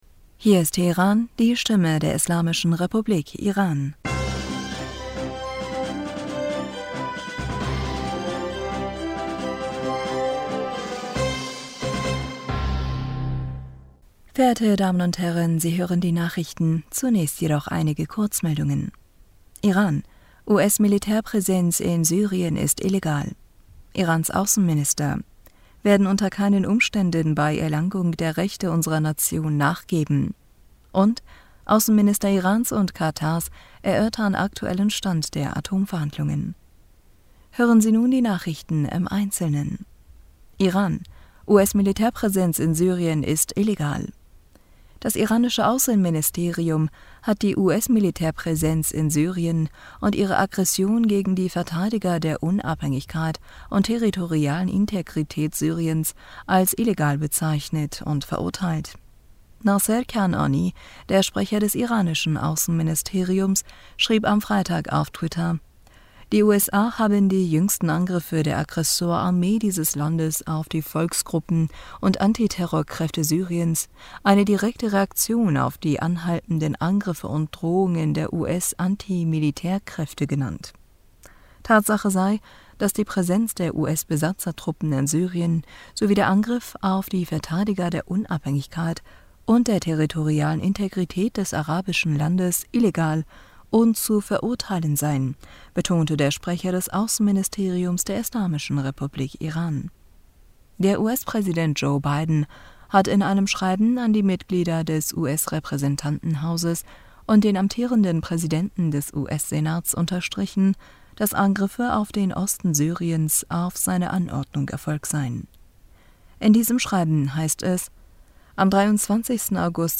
Nachrichten vom 27. August 2022
Die Nachrichten von Samstag, dem 27. August 2022